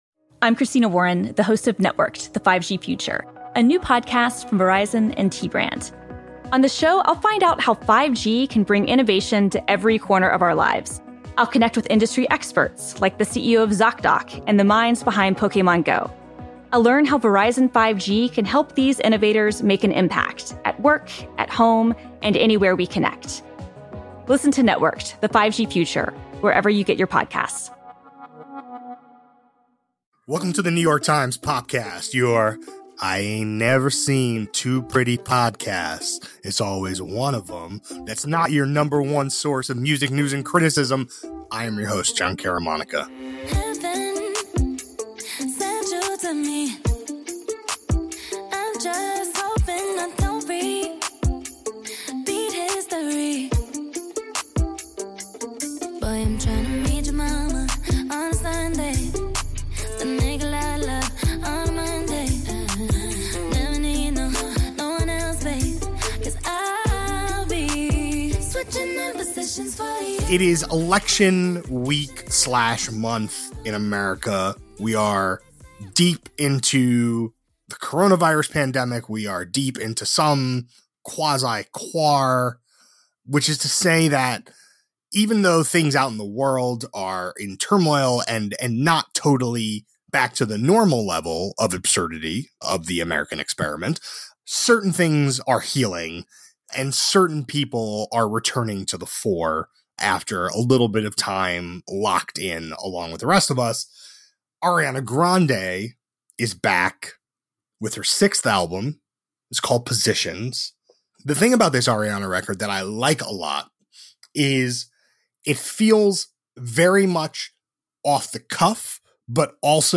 A conversation about her unique route to the top of the charts — and what’s next.